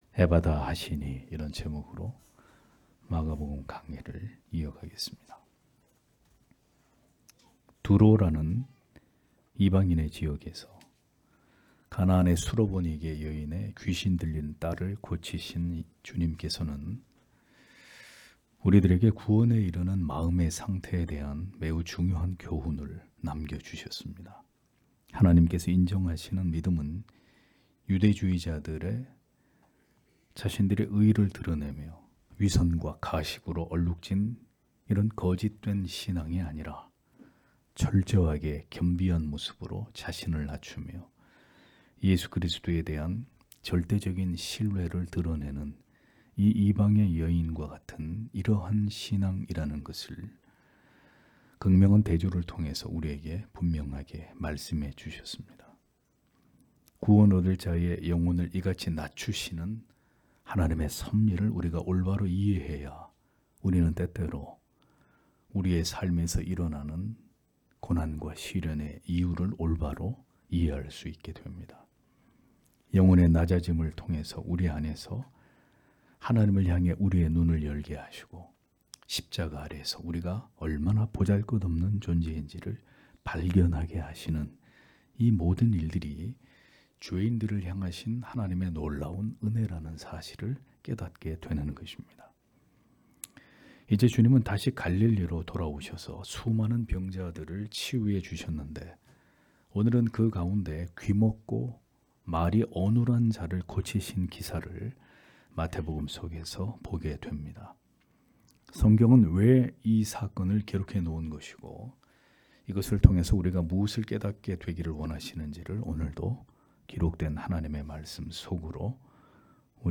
주일오전예배 - [마가복음 강해 28] 에바다 하시니 (막 7장 31-37절)